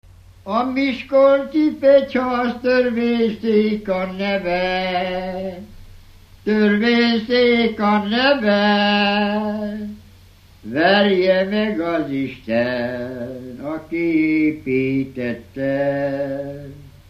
Felföld - Borsod vm. - Borsodszentmárton
ének
Műfaj: Rabének
Stílus: 1.1. Ereszkedő kvintváltó pentaton dallamok